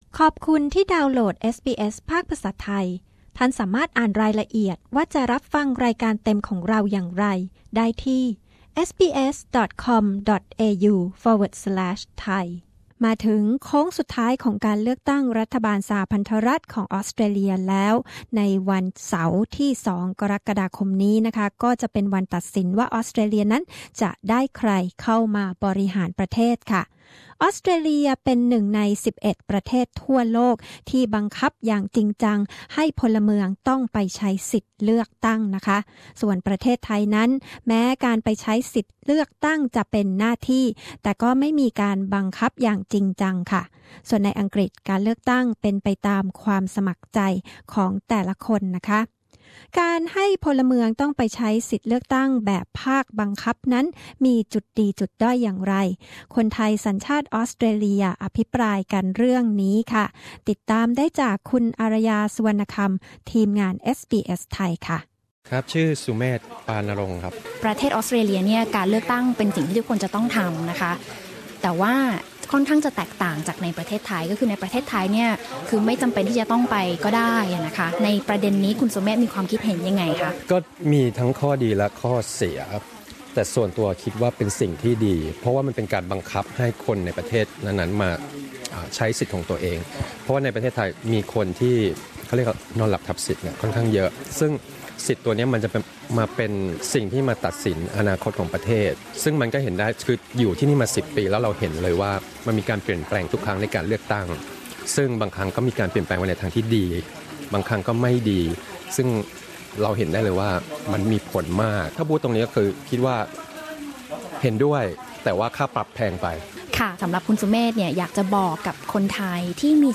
ออสเตรเลีย เป็นหนึ่งใน 11 ประเทศทั่วโลก ที่บังคับอย่างจริงจังให้พลเมืองต้องไปใช้สิทธิเลือกตั้ง ส่วนประเทศไทยนั้น แม้การไปใช้สิทธิเลือกตั้งจะเป็นหน้าที่ แต่ไม่มีการบังคับ การให้พลเมืองต้องไปใช้สิทธิเลือกตั้งแบบบังคับนั้นมีจุดดีจุดด้อยอย่างไร คนไทยสัญชาติออสเตรเลียอภิปรายประเด็นนี้กับ เอสบีเอส ไทย